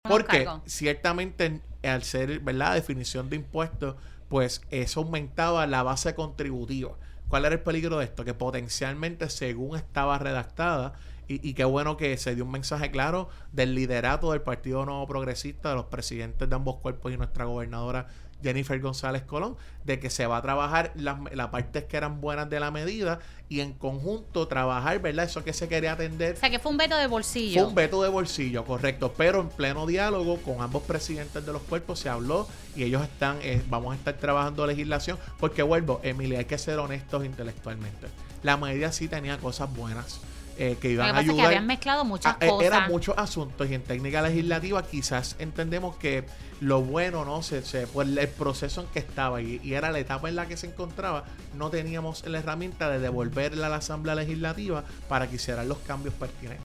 asesor legislativo
entrevista